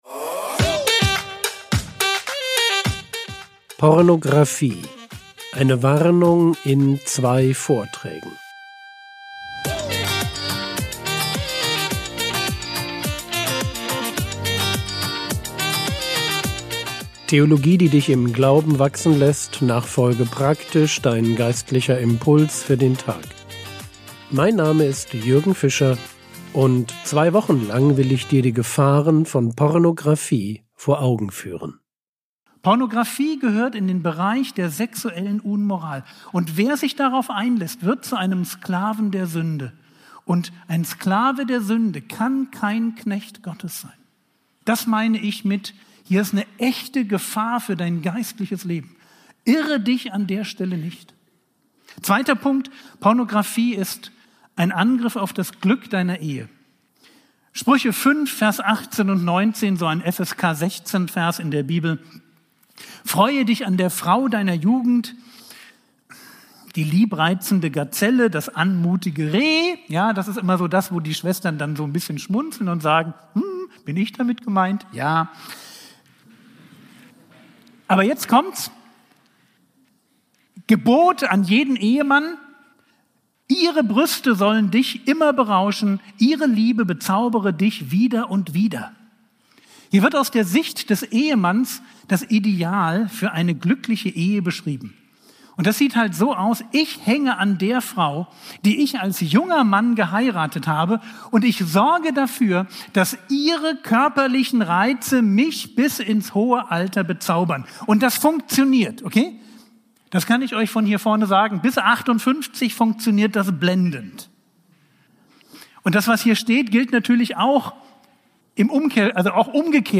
Frogwords Mini-Predigt podcast